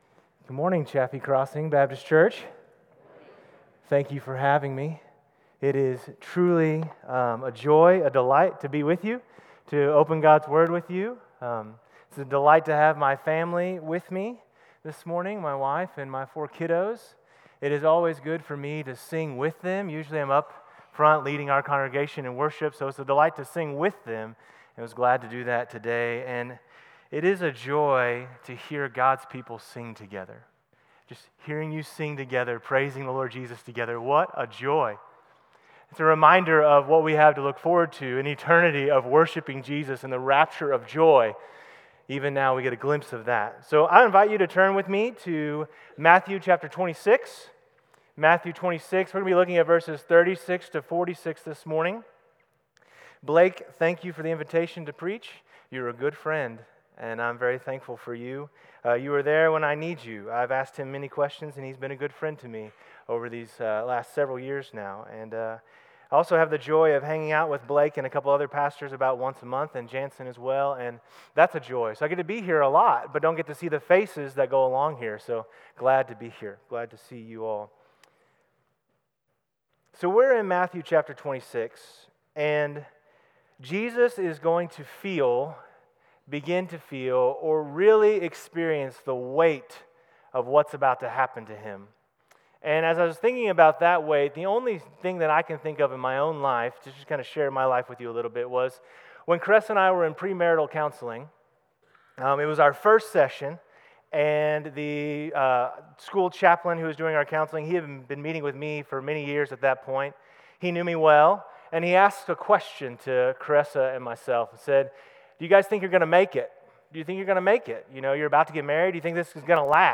CCBC Sermons Thy Will, Not Mine (Matthew 26:36-46) Mar 30 2025 | 00:41:02 1x 00:00 / 00:41:02 Subscribe Share Apple Podcasts Spotify Overcast RSS Feed Share Link Embed